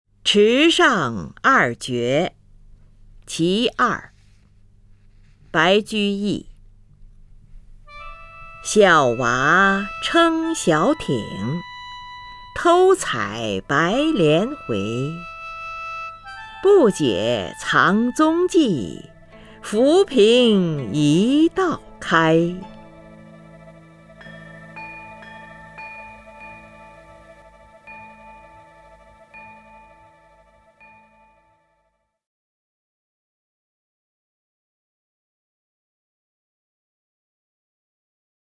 林如朗诵：《池上二绝·其二》(（唐）白居易) （唐）白居易 名家朗诵欣赏林如 语文PLUS